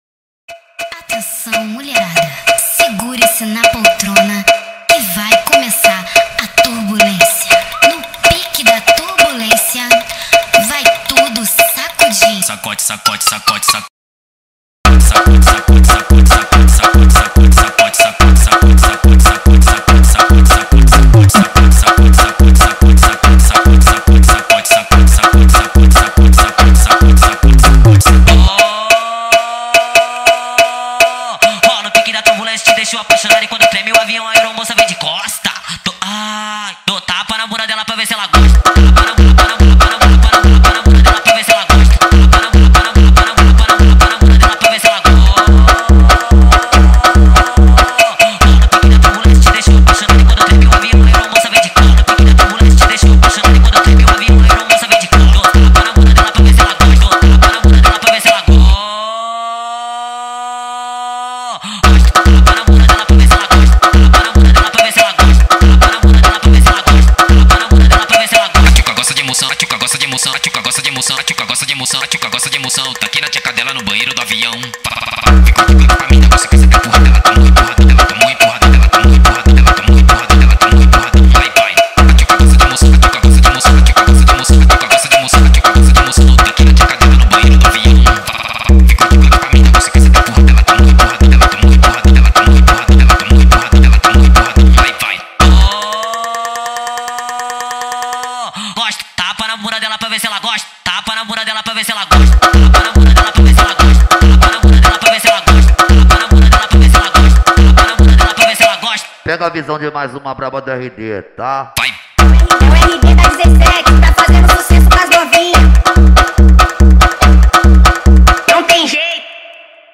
ورژن Sped Up و تند شده
فانک